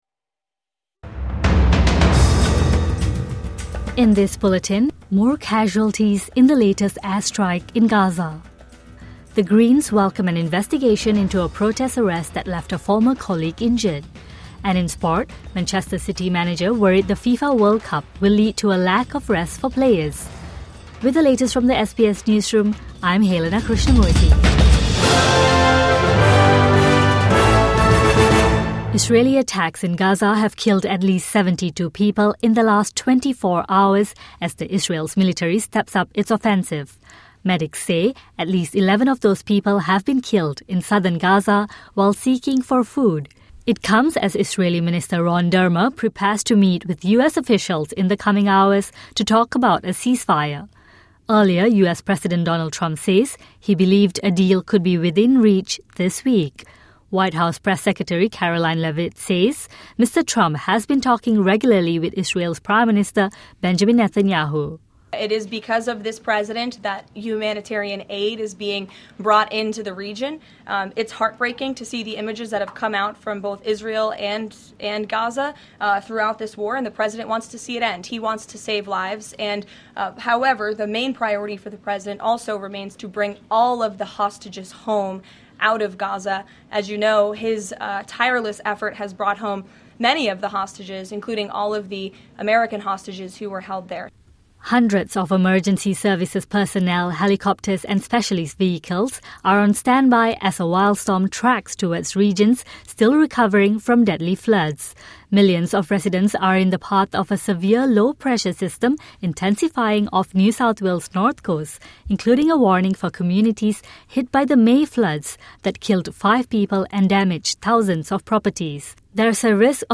Scores killed in latest Israeli airstrike in Gaza | Morning Bulletin 1 July